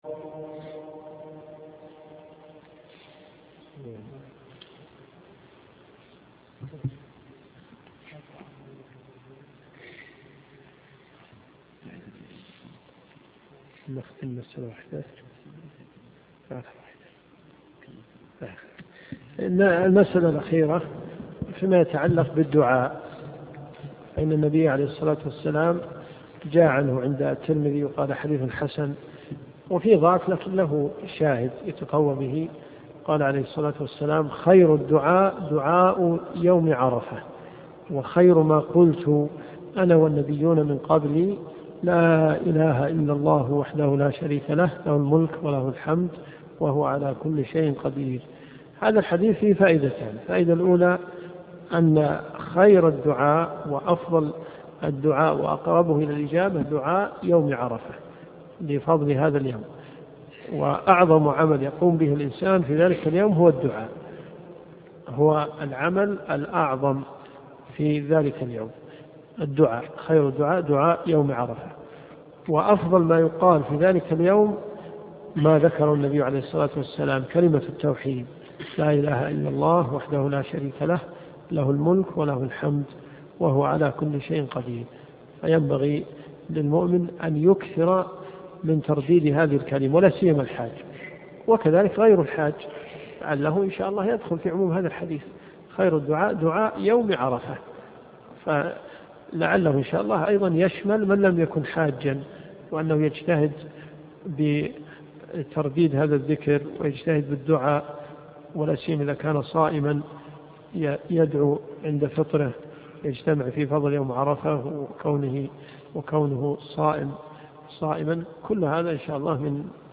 قسم المحاضرات . محاضرة عن يوم عرفة - بجامع الاميرة نورة بنت عبدالله حي النخيل .